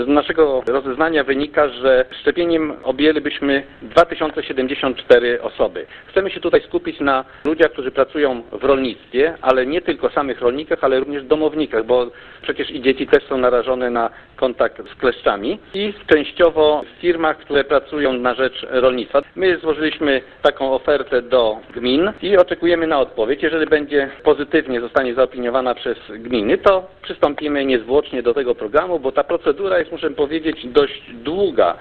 Jednak jak zaznacza w rozmowie z Radiem 5 Marian Świerszcz, starosta olecki czy program zostanie uruchomiony zależy w dużej mierze od wójtów czterech gmin leżących na terenie powiatu.